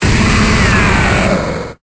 Cri de Steelix dans Pokémon Épée et Bouclier.